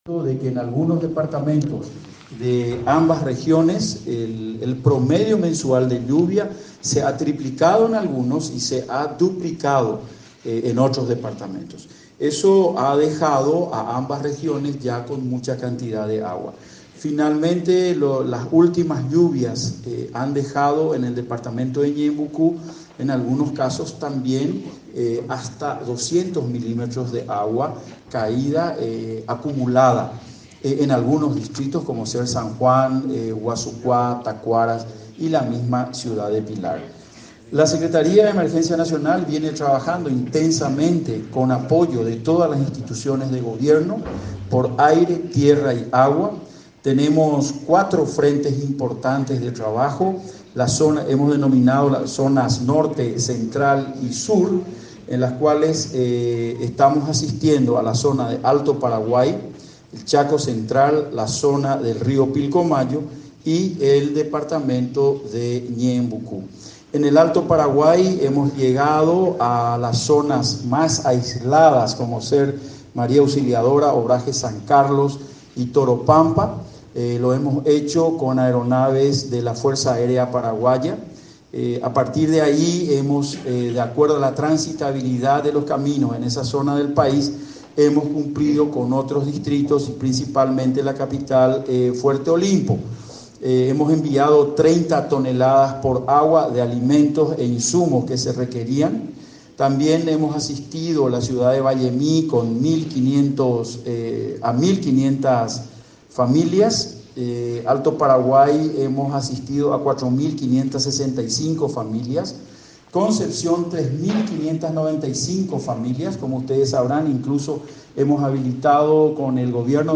10-JOAQUÍN-ROA-EN-CONFERENCIA.mp3